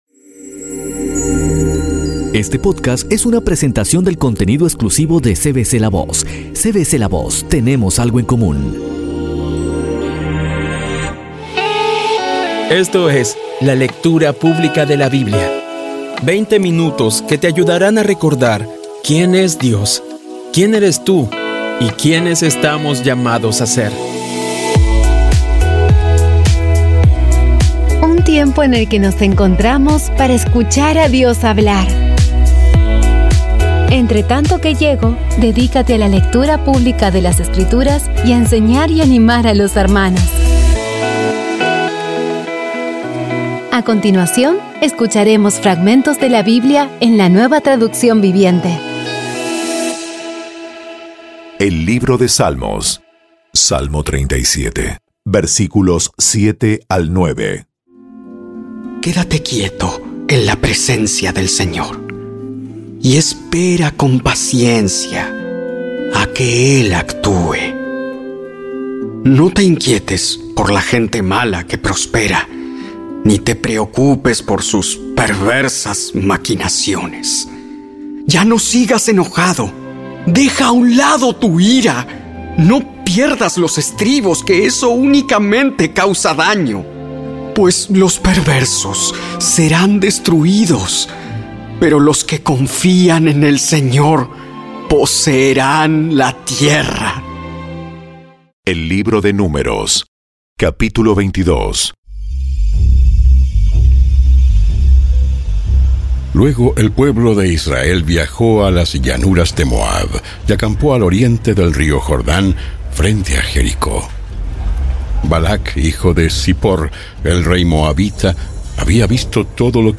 Con tan solo veinte minutos diarios, vas cumpliendo con tu propósito de estudiar la Biblia completa en un año. Poco a poco y con las maravillosas voces actuadas de los protagonistas vas degustando las palabras de esa guía que Dios nos dio.